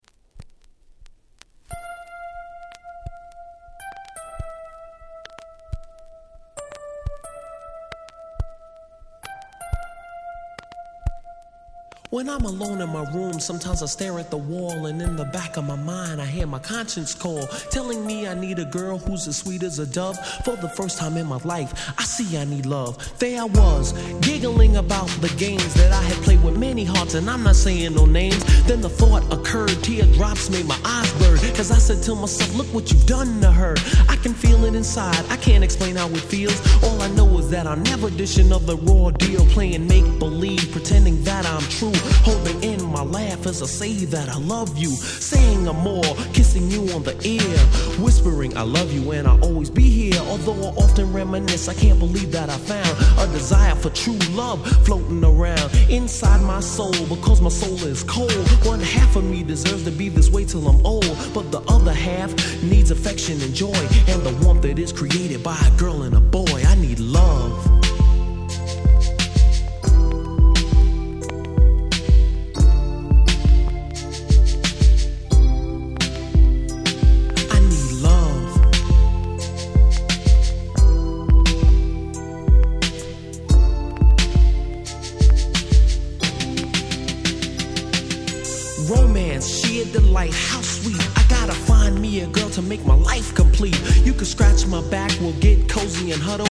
【Media】Vinyl 12'' Single
【Condition】C (スリキズ多め。チリノイズが入る箇所があります。試聴ファイルにてご確認願います。)
87' Smash Hit Hip Hop !!